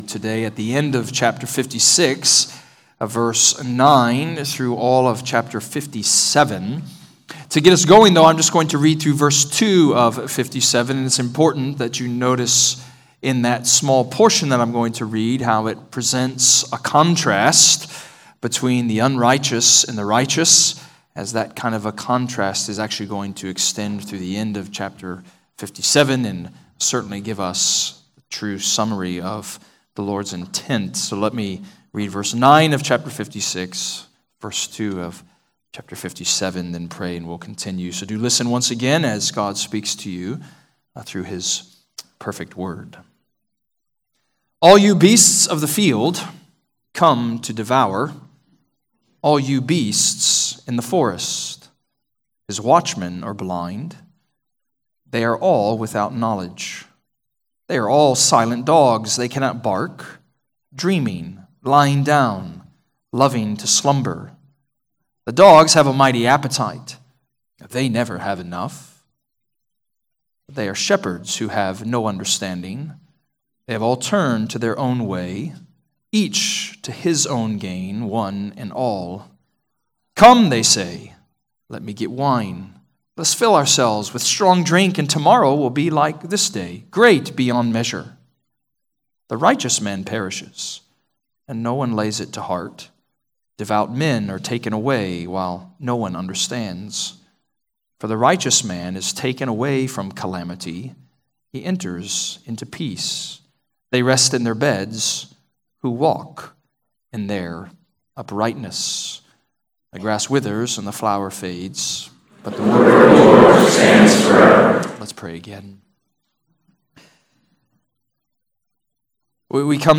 Redeemer Presbyterian Church: Sermon Audio
Download sermons from Redeemer Presbyterian Church in McKinney, TX.